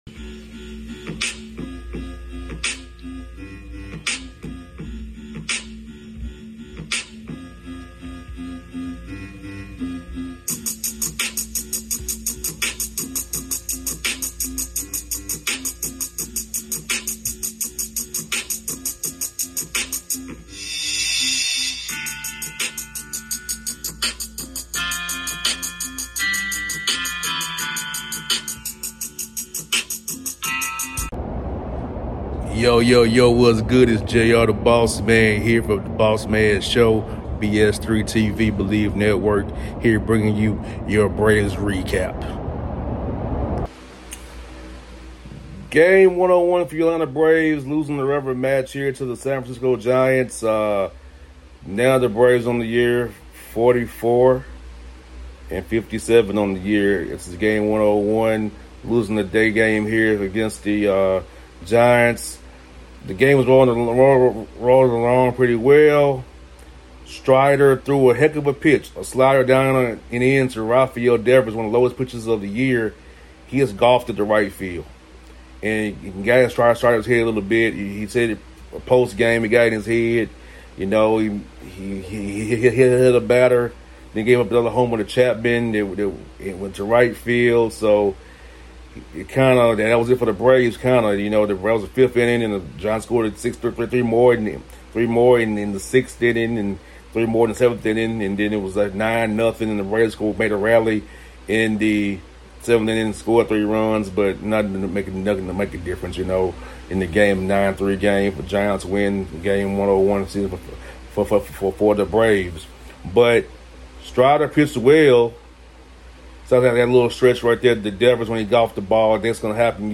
07-23-25 Atlanta Braves Postgame Recap featuring Pitcher Spencer Strider and Manager Brian Snitker